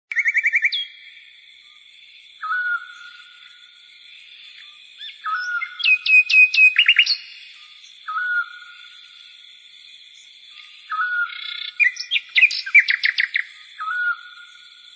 Sound Effects
Bird Sounds